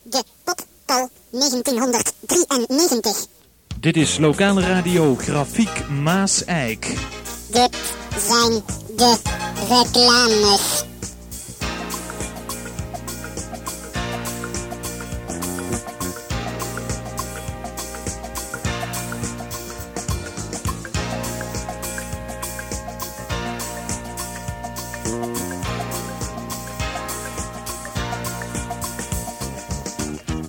download Reclames jingle
Reclames jingle.mp3